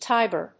/tάɪbɚ(米国英語), tάɪbə(英国英語)/